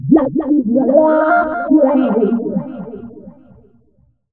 VOX FX 1  -R.wav